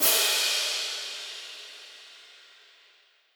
Crashes & Cymbals
Crash (3).wav